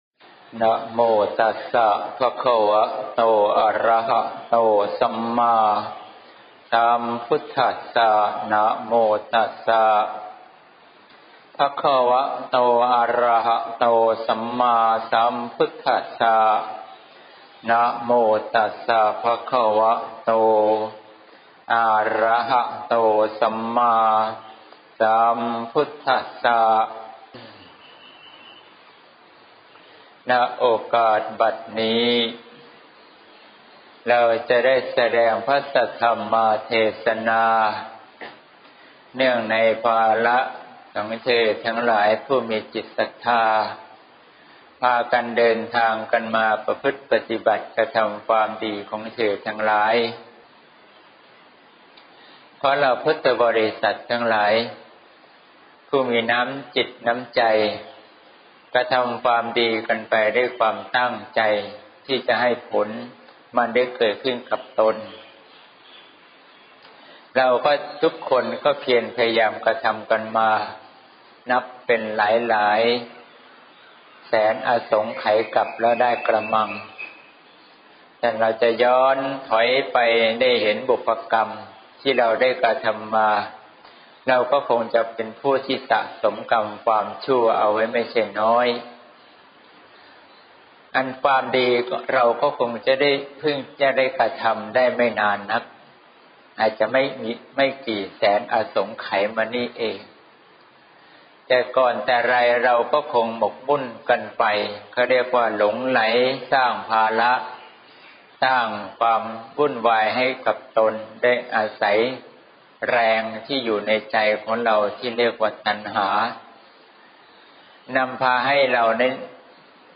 เทศน์ (เสียงธรรม ๓๐ มี.ค. ๖๘)